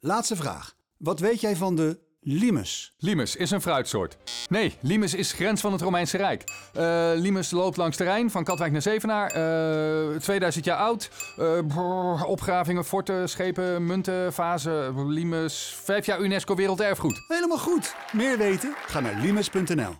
De radiospot is vormgegeven in de stijl van de populaire kennisquiz De Slimste Mens. In een razendsnel vragenvuur krijgt de luisteraar te horen wat de Limes nu werkelijk is, en vooral dat het niets te maken heeft met een limoen.